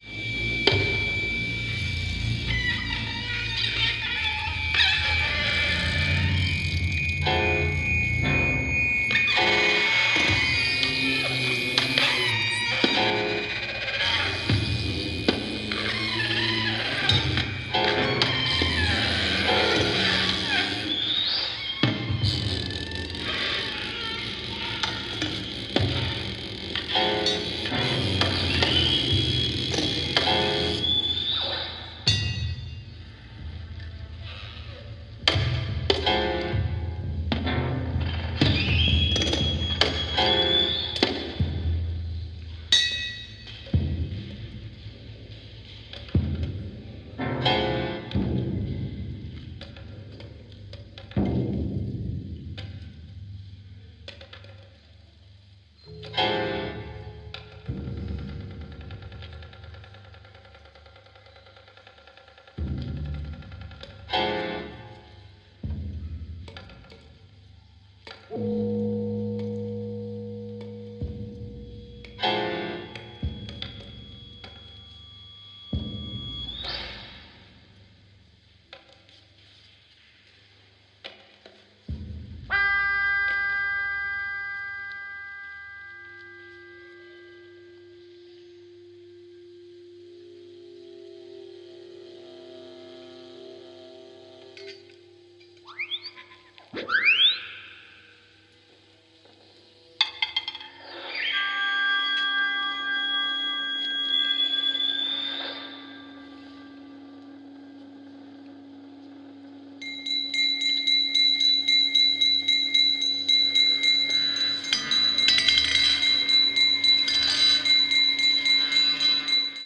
improvisation in large ensembles